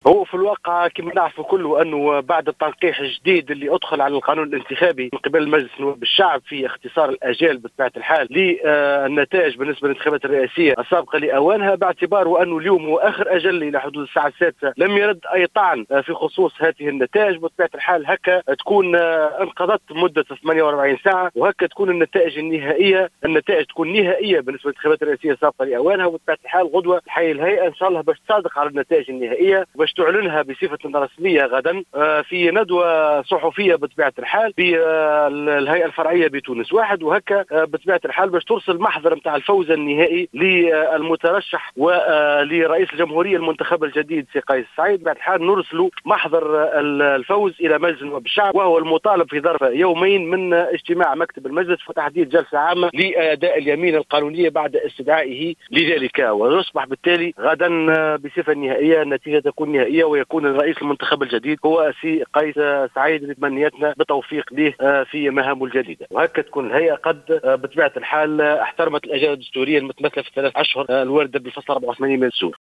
أكد عضو الهيئة العليا المستقلة للانتخابات محمد التليلي المنصري اليوم الأربعاء 16 أكتوبر 2019، في تصريح للجوهرة اف ام أن المحكمة الإدارية لم تتلق إلى حدود الساعة السادسة من مساء اليوم أي طعون متعلقة بالدور الثاني للرئاسية السابقة لأوانها.